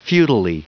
Prononciation du mot futilely en anglais (fichier audio)
Prononciation du mot : futilely